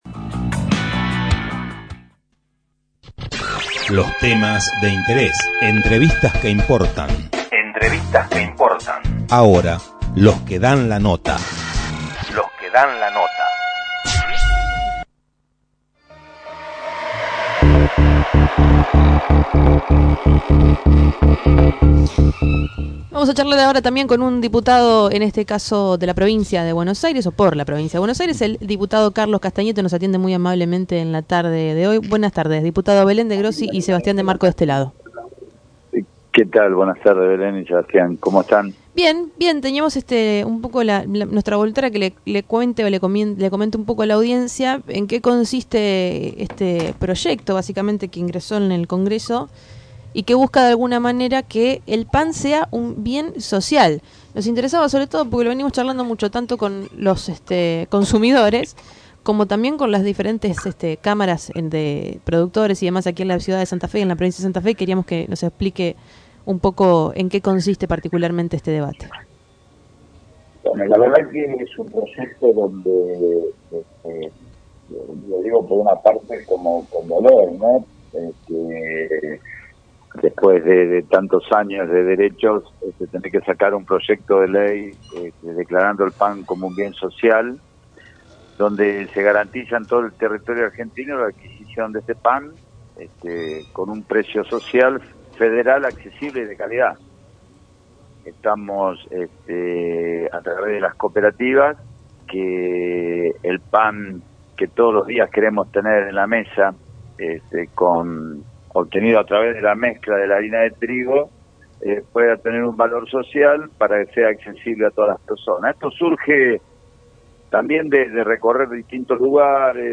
Tren Urbano dialogó con el Diputado Nacional Carlos Castagneto quien presentó un proyecto de ley denominado Pan Social, que apunta a establecer el pan en todas las mesas de los argentinos.